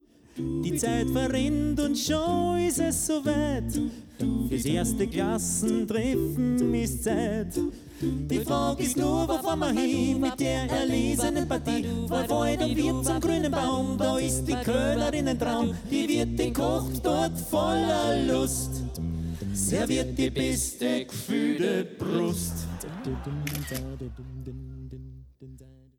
a cappella-Konzertpackage